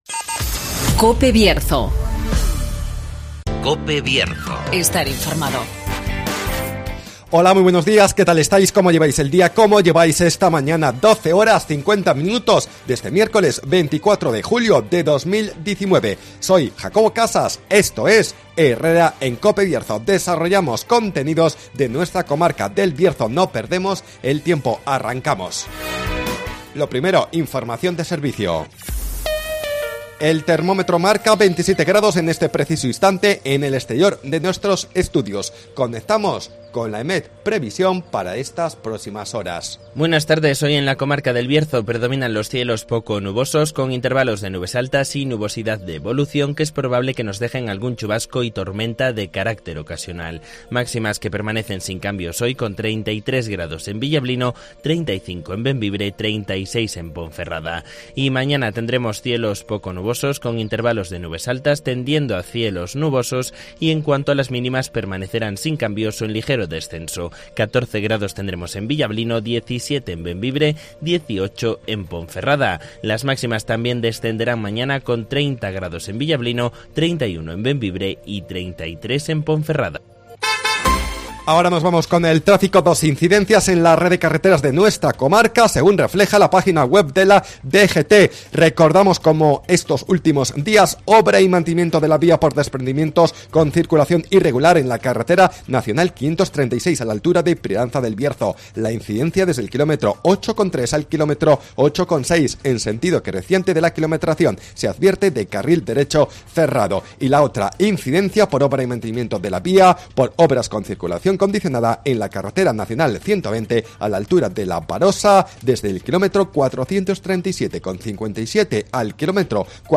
Programas Bierzo ACTUALIDAD Herrera en COPE Bierzo 24-07-19 de 12:50 a 13 horas Repasamos la actualidad y realidad del Bierzo. Espacio comarcal de actualidad, entrevistas y entretenimiento.